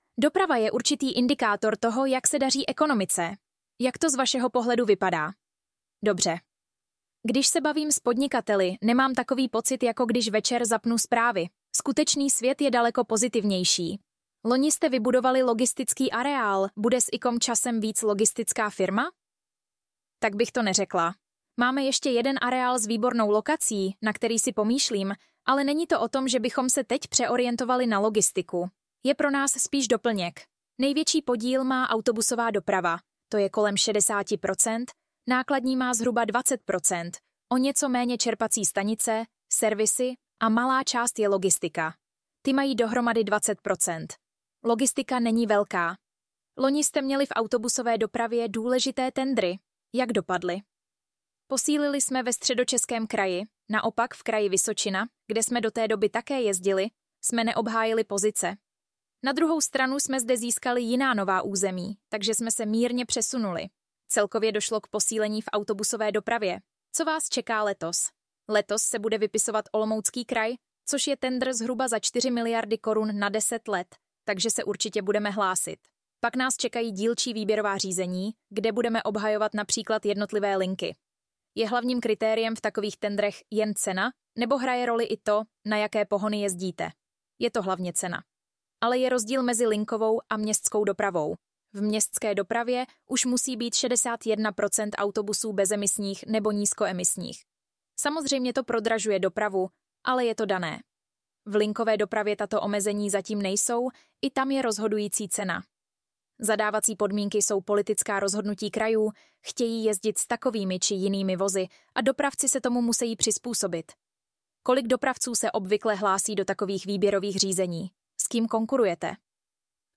U nás na Modré vlně máte možnost si rozhovor poslechnout zcela zdarma v našem PODCASTU.